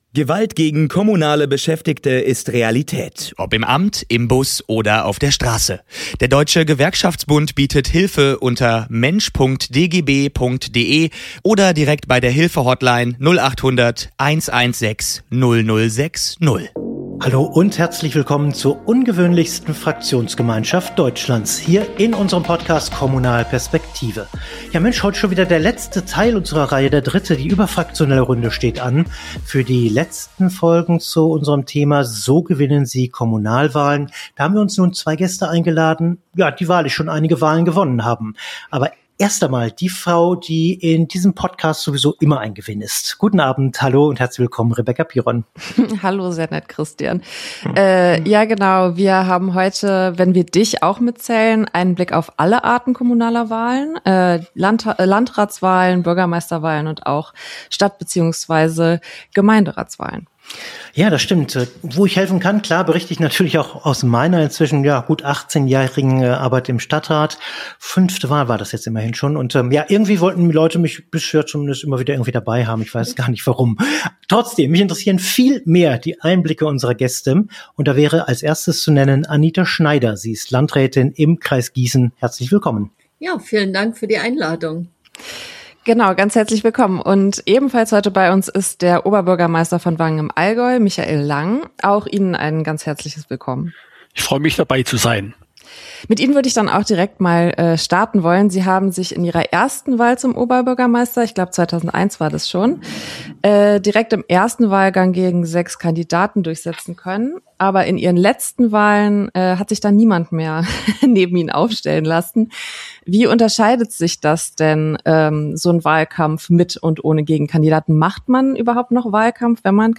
In dieser Folge sprechen erfahrene Bürgermeister und Landräte über ihre erfolgreichsten Strategien, überraschende Kampagnenideen und typische Fehler im Wahlkampf.